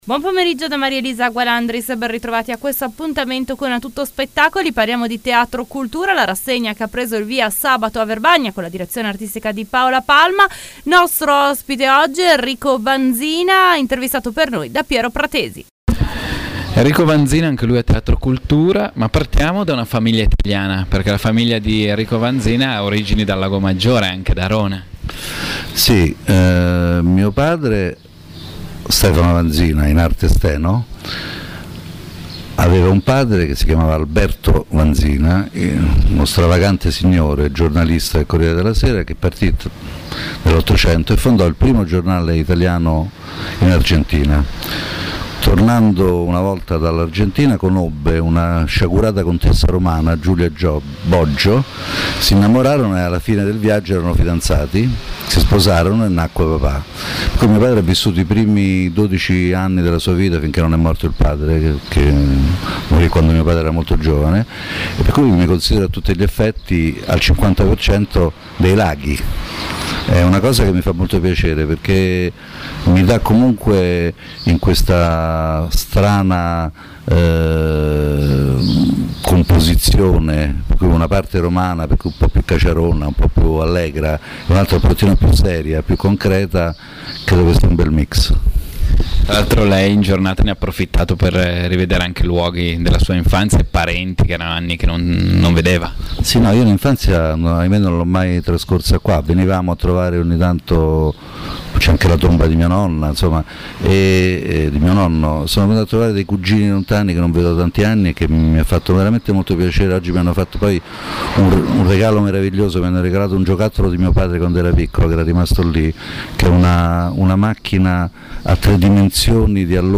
Sabato 11 giugno 2011 intervista a Enrico VANZINA realizzata da Rvl la Radio